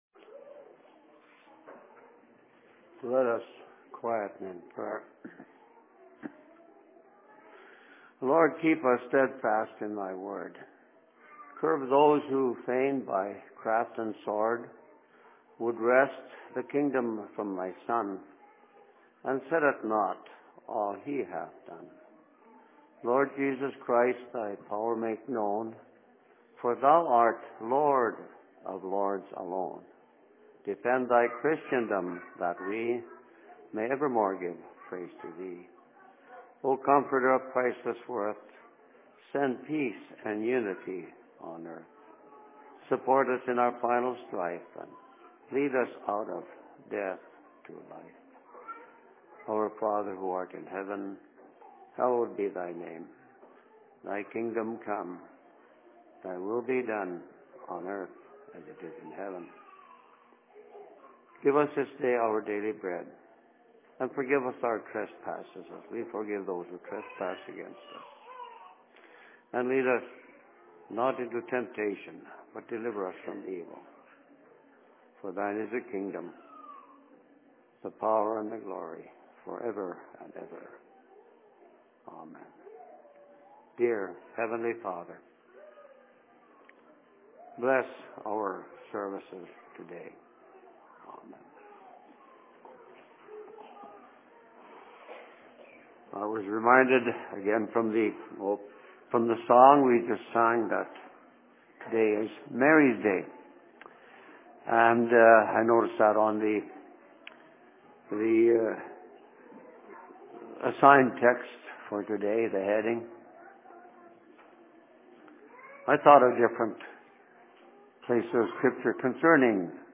Sermon in Outlook 25.03.2012